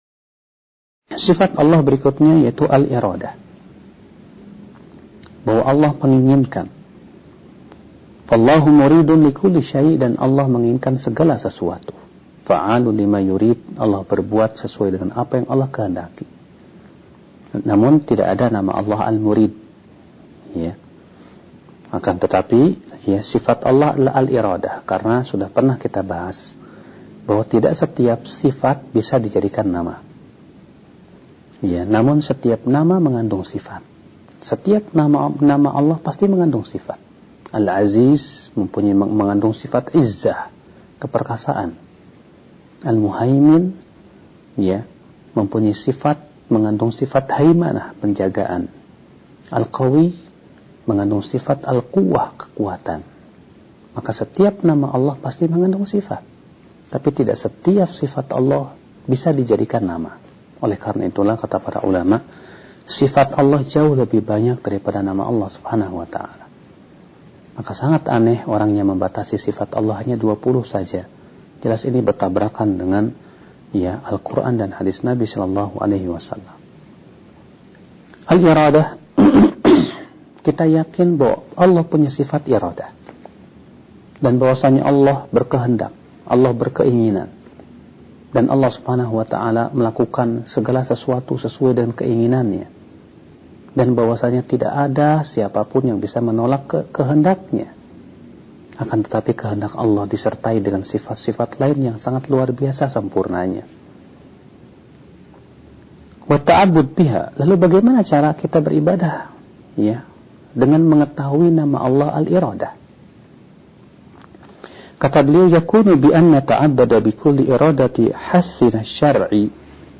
Berikut ini merupakan rekaman dari ceramah agama